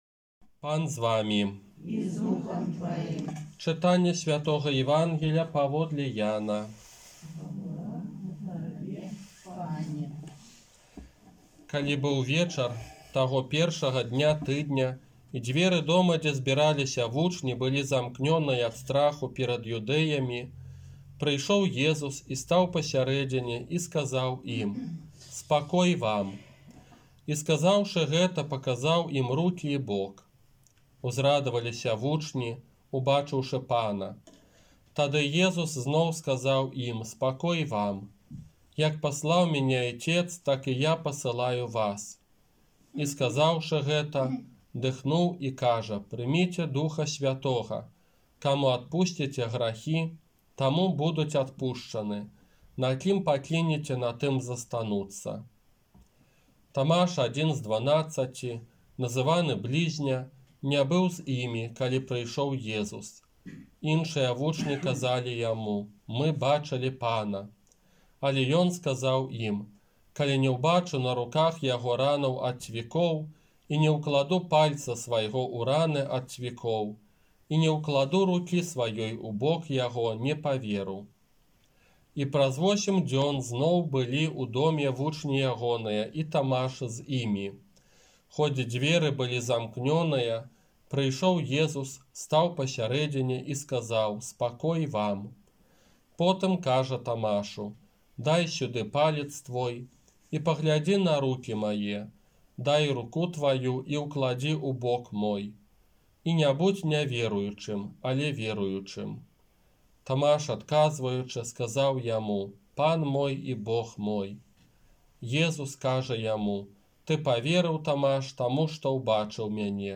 ОРША - ПАРАФІЯ СВЯТОГА ЯЗЭПА
Казанне на Свята Божай Міласэрнасці